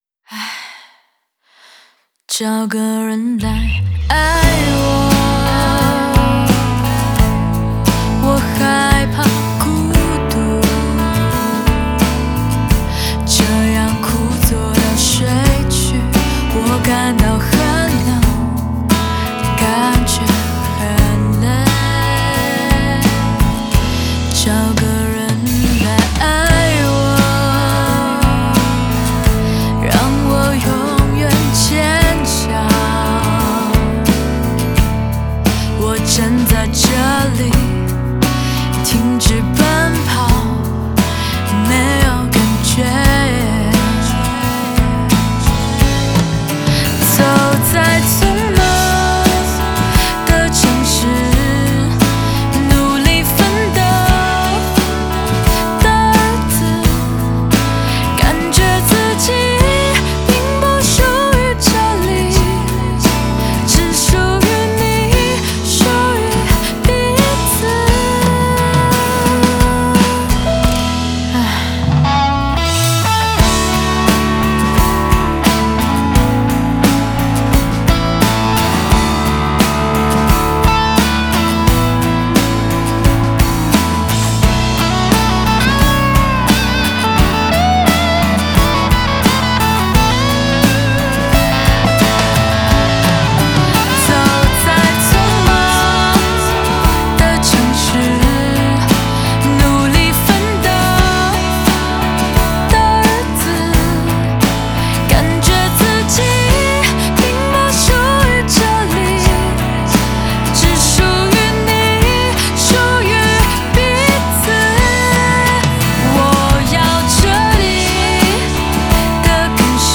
Ps：在线试听为压缩音质节选，